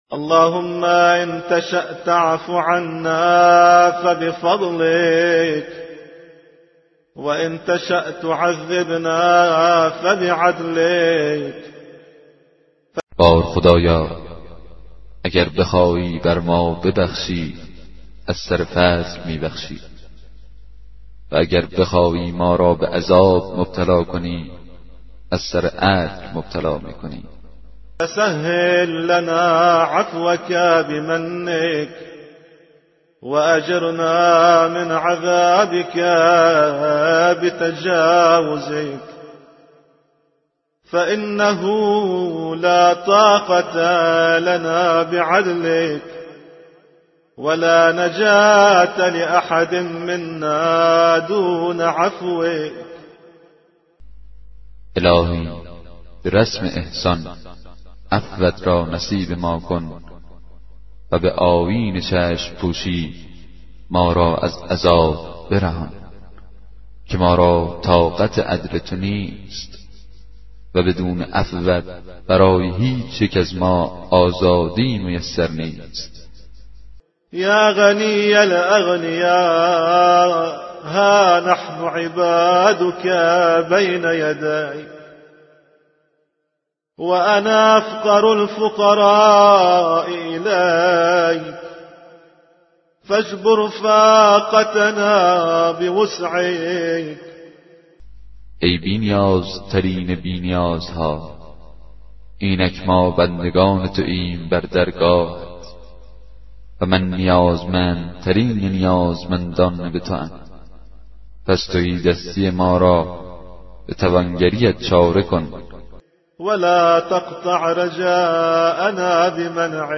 کتاب صوتی دعای 10 صحیفه سجادیه